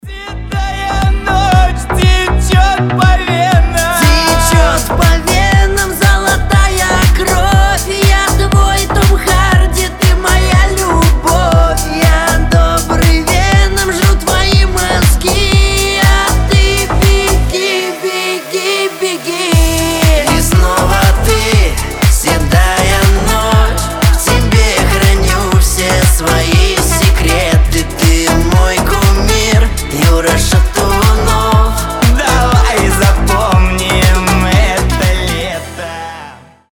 • Качество: 320, Stereo
веселые
смешные